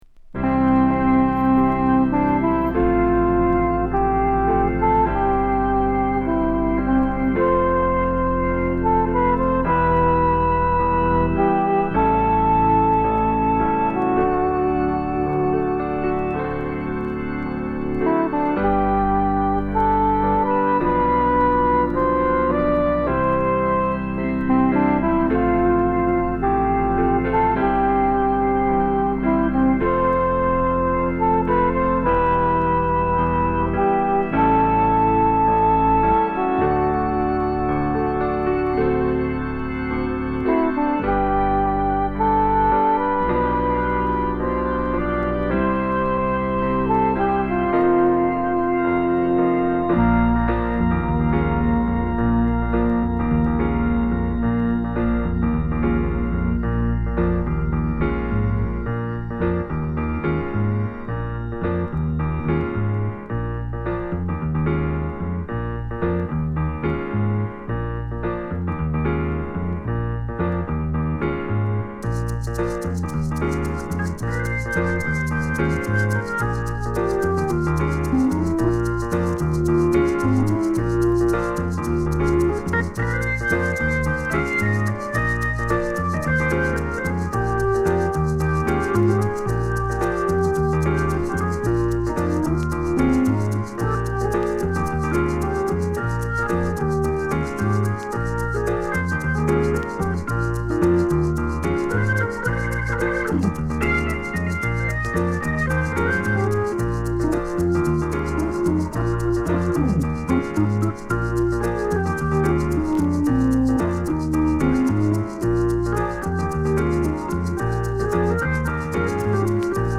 ドラム無しで展開されるアシッドフォーク作品でピアノとオルガンが絡み